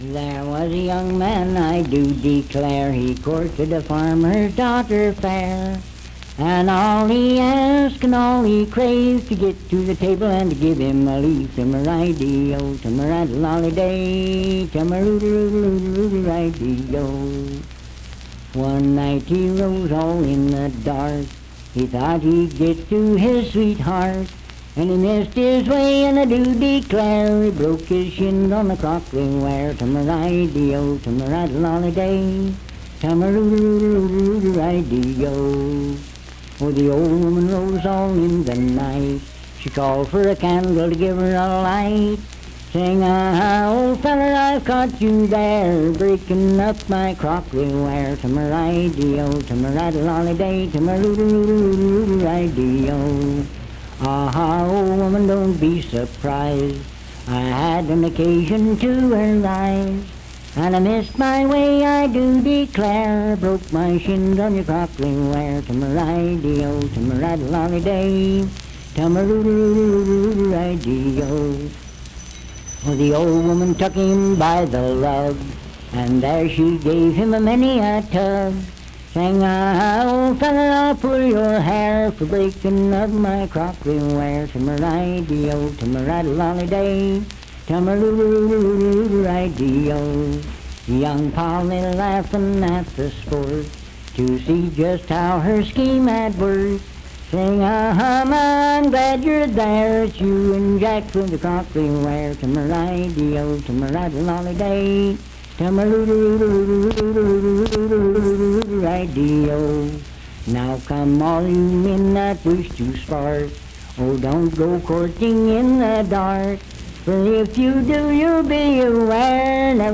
Unaccompanied vocal music
Verse-refrain 7(6w/R). Performed in Sandyville, Jackson County, WV.
Voice (sung)